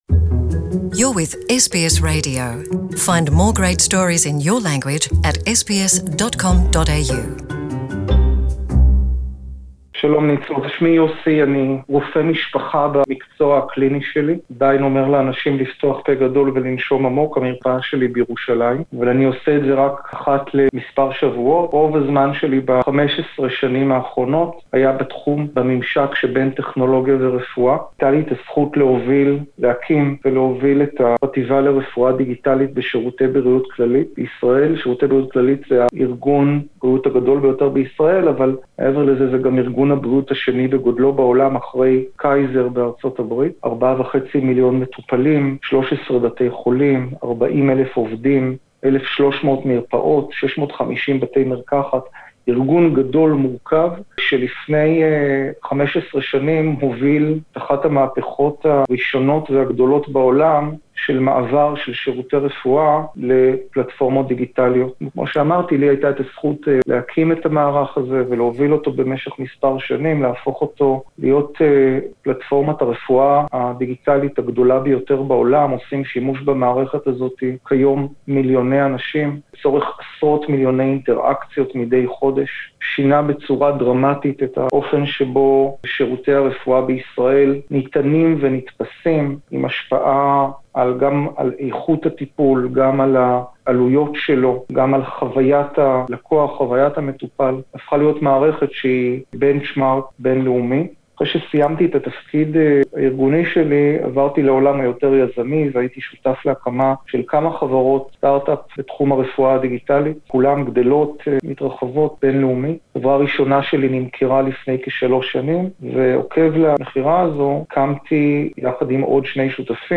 We discussed with him the issues concerning keeping confidential the health records and the relationship of patients with doctors in the digital era. Interview in Hebrew